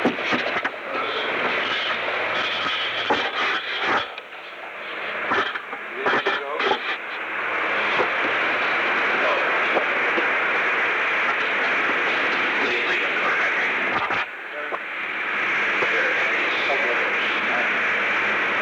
Recording Device: Oval Office
NARA Description: On January 29, 1972, President Richard M. Nixon and Alexander P. Butterfield met in the Oval Office of the White House at 12:10 pm. The Oval Office taping system captured this recording, which is known as Conversation 660-009 of the White House Tapes.